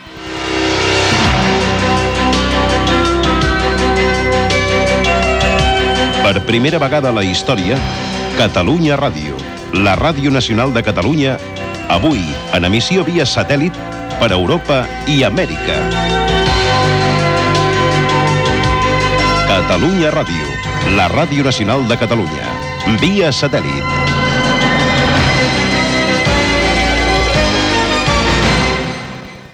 Primera emissió via satèl·lit
Satèl·lits emprats i el territori que abasten. Paraules de Jordi Vilajoana, director de la Corporació Catalana de Ràdio i Televisió.